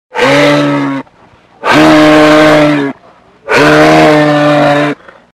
Звуки лося
Звук рева лося